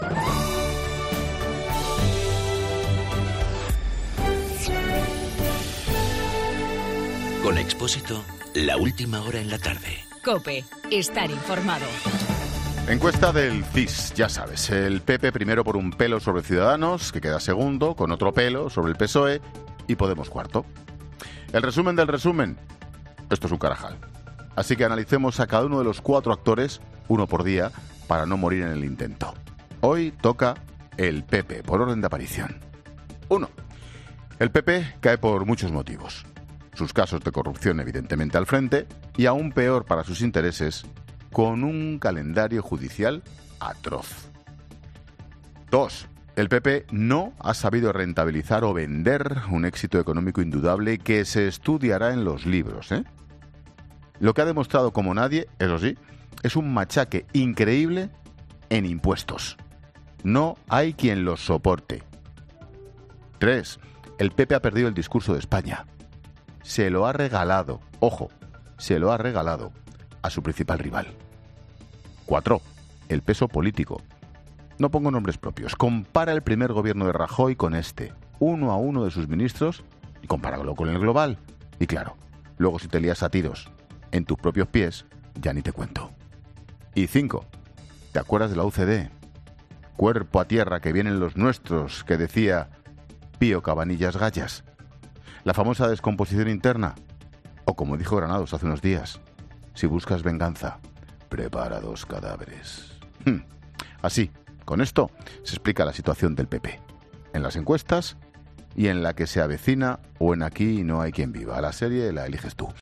Monólogo de Expósito
El comentario de Ángel Expósito sobre barómetro del CIS.